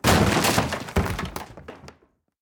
destroy7.ogg